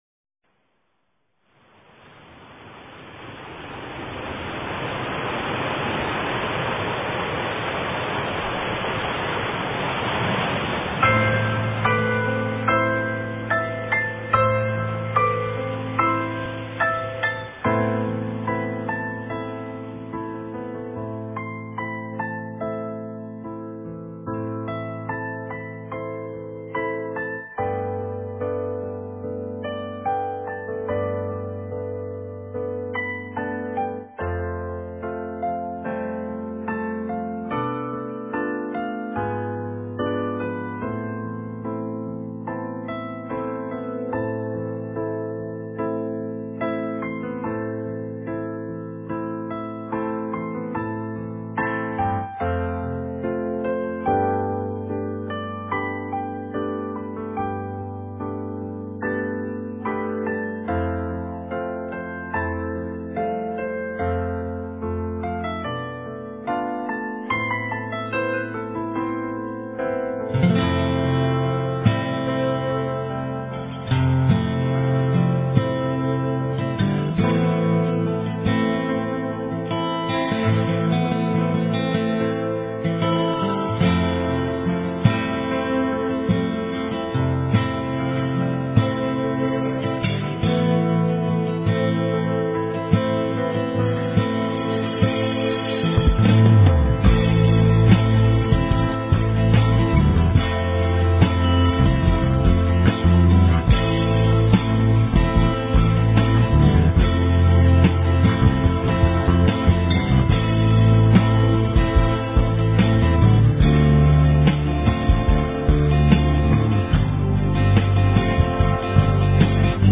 آهنگ بی کلام با گیتار برای جشن تولد